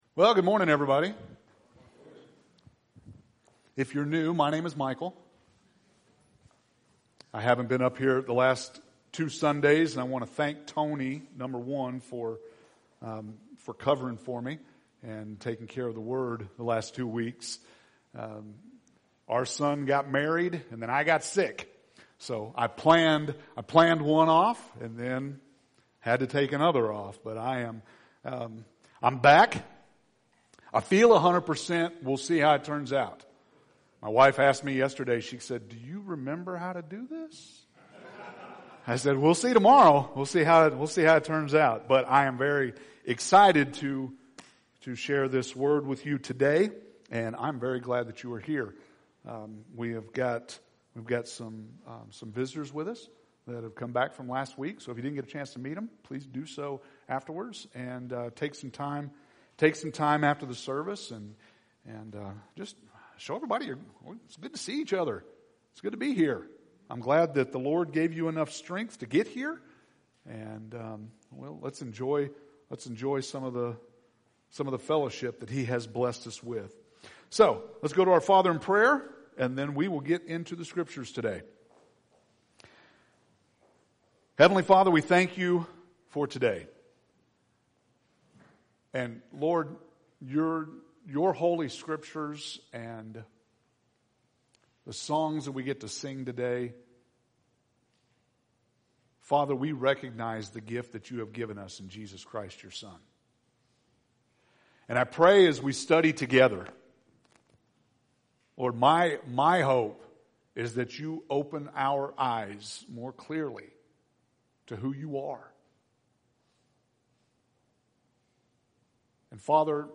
August 11th – Sermons